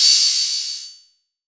DDW2 OPEN HAT 6.wav